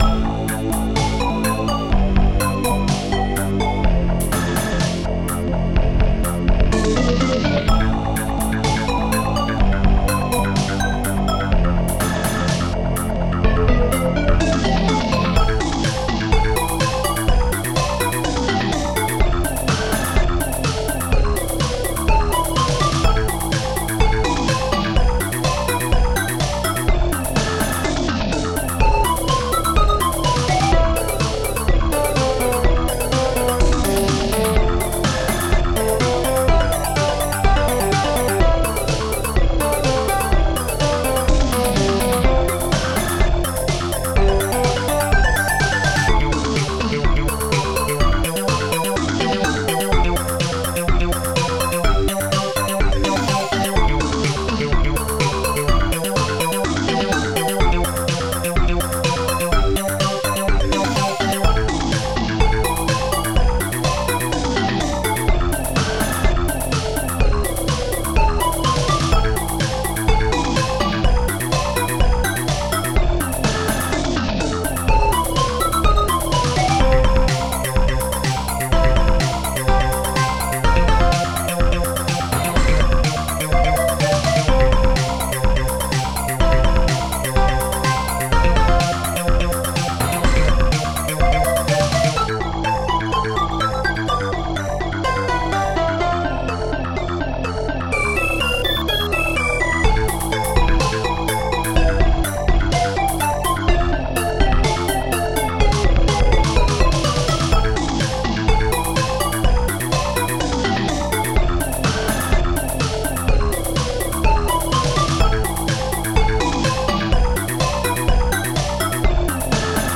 Protracker and family
st-04:bassdrum7
st-01:popsnare2
st-03:hammerhihat